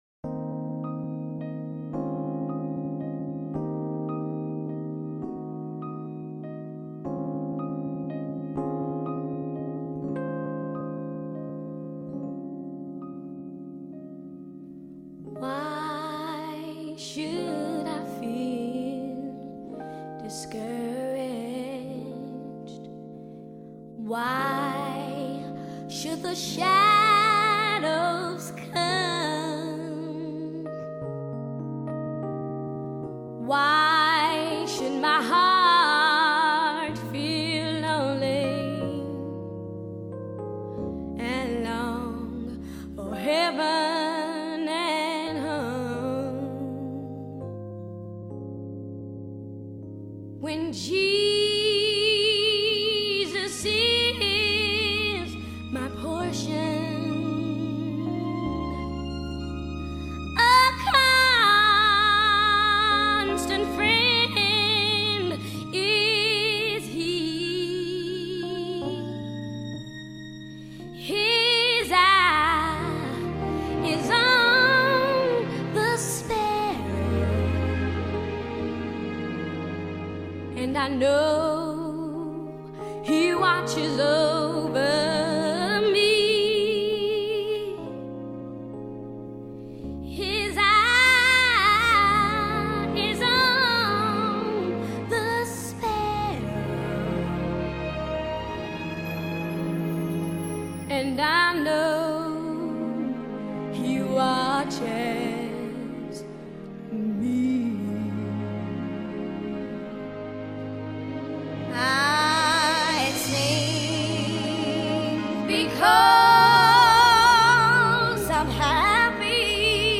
mixed keys